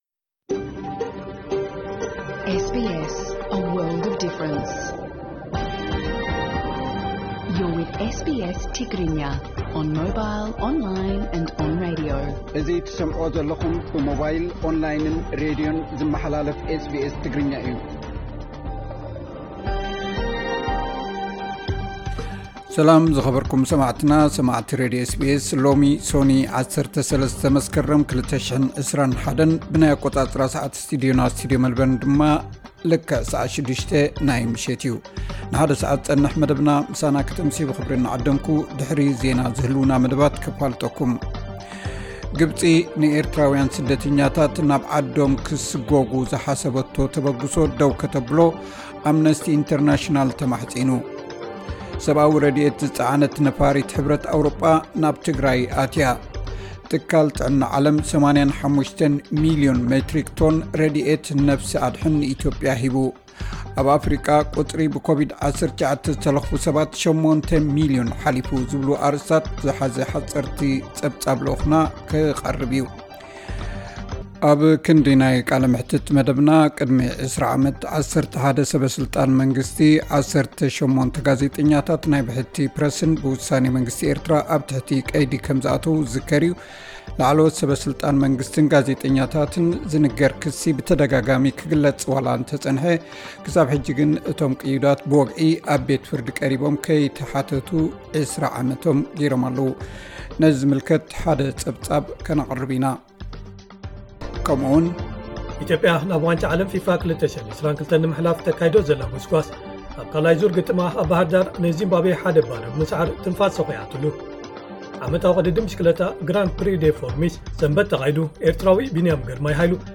ዕለታዊ ዜና 13 መስከረም 2021 SBS ትግርኛ